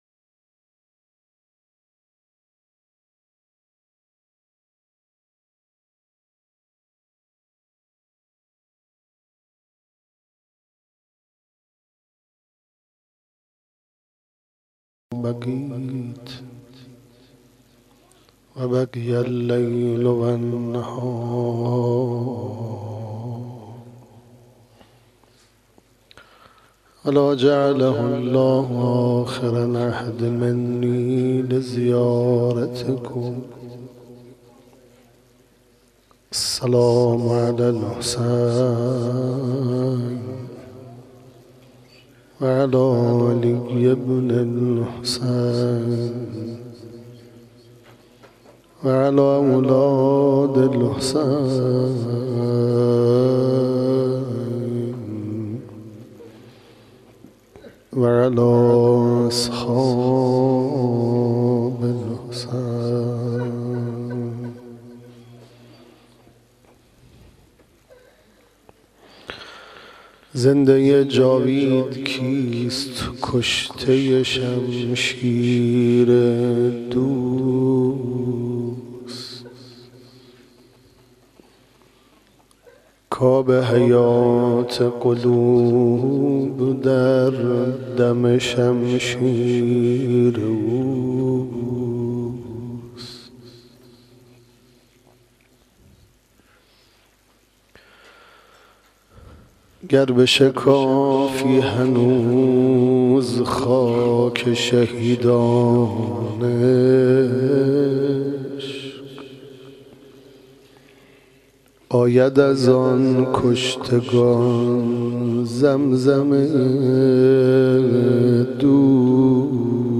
حسینیه قائم آل محمد(عج)/